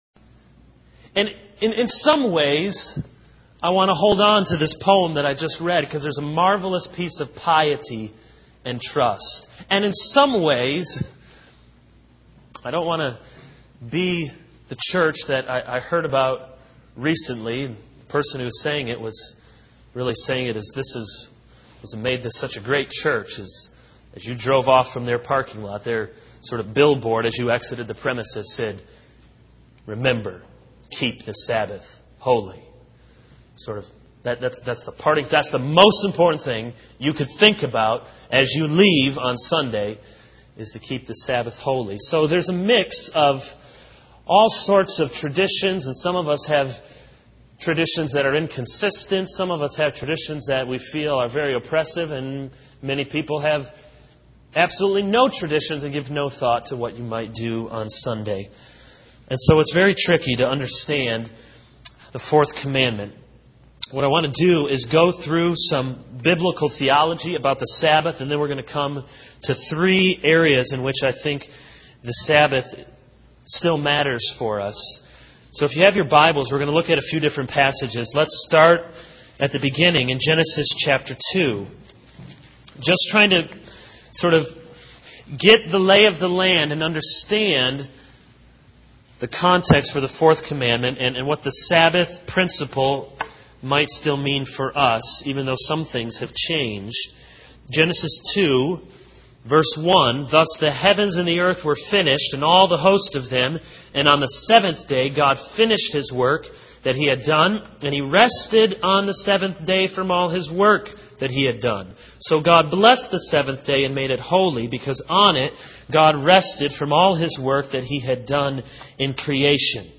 This is a sermon on Exodus 20:1-17 - Keep the Sabbath holy.